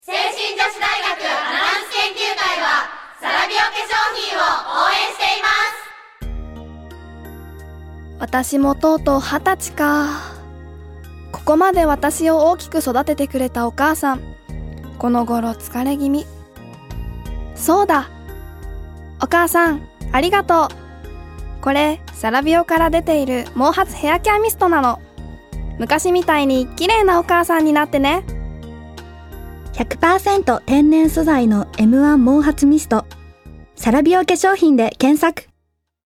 どれも清々しいお声で、皆さんの笑顔が浮かぶステキなＣＭです！
聖心女子大学アナウンス研究会による「ラジオCM」